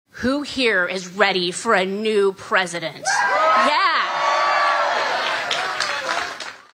Bird made the announcement just before introducing Trump to a crowd in Adel this (Monday) afternoon.